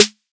SOUTHSIDE_snare_og_hard.wav